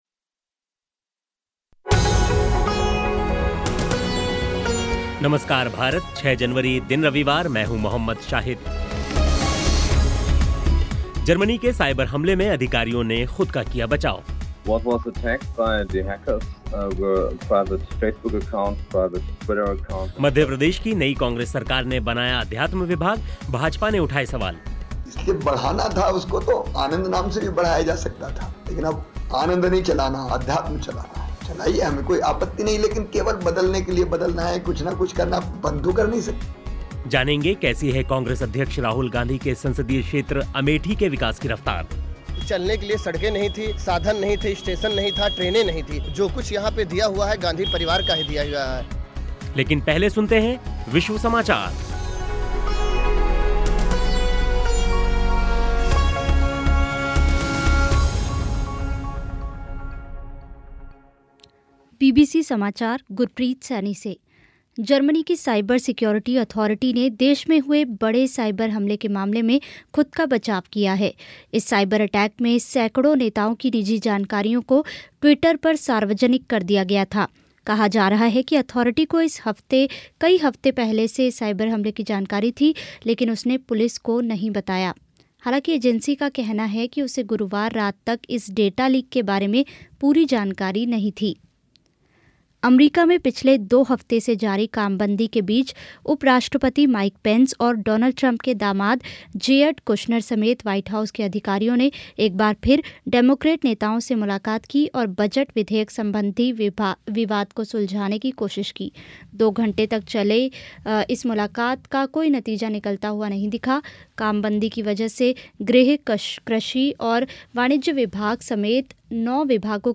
अख़बारों की समीक्षा भी होगी लेकिन पहले विश्व समाचार सुनिए.